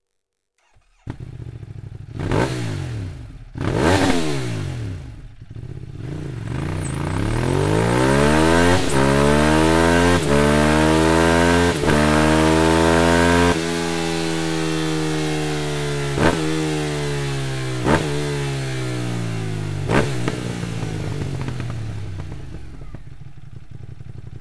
Mit dem Akrapovic Slip-On System bekommt Ihr Scooter diesen unverkennbaren tiefen und satten Akrapovic Sound, und auch das äußere Erscheinungsbild ändert sich dramatisch.
Sound Akrapovic Slip-On